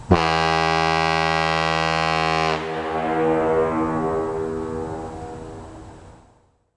ShipHorn_01.mp3